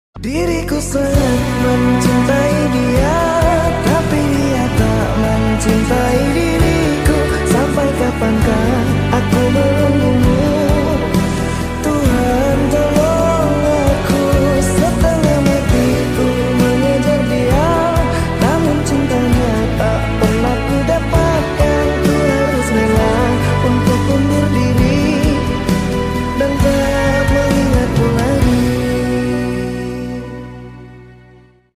Echo Mix